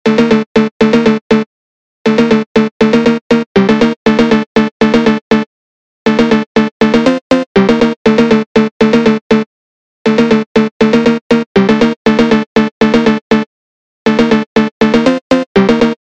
Brazilian Phonk